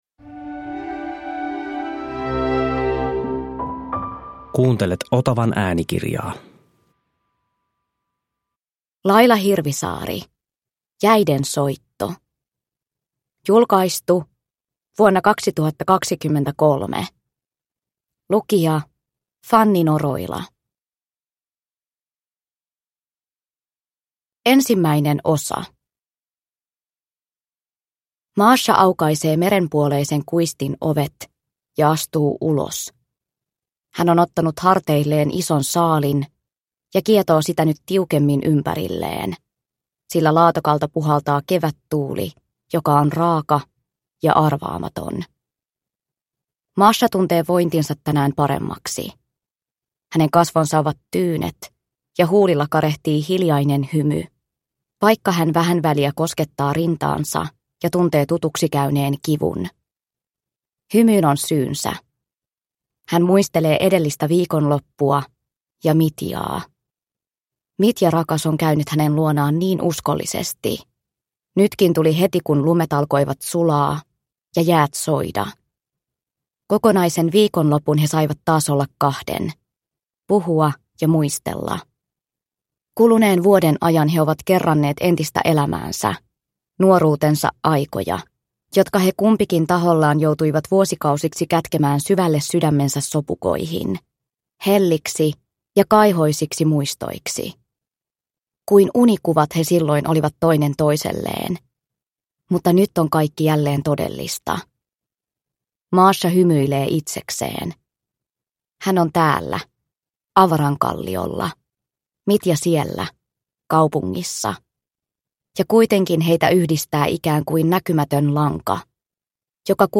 Jäiden soitto – Ljudbok – Laddas ner